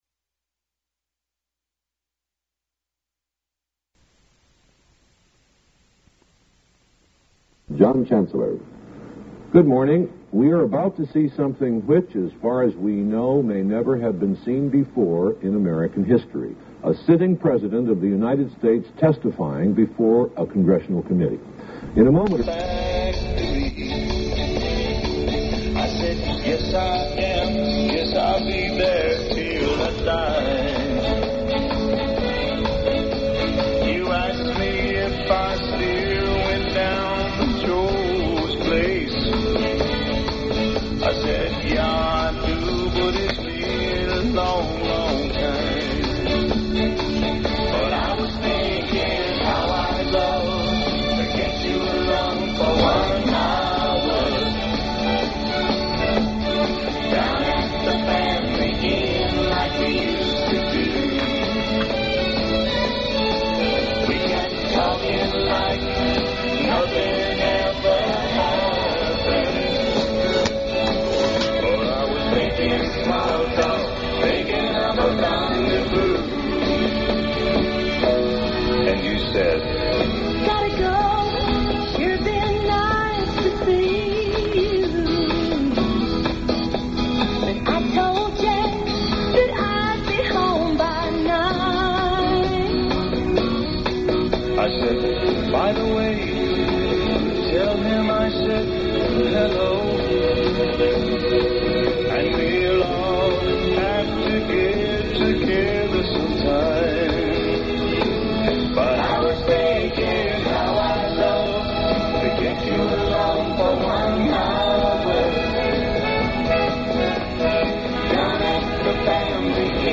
President Gerald R. Ford describes his pardon of Richard Nixon on September 8, 1974 to a subcommittee of the House of Representatives Judiciary Committee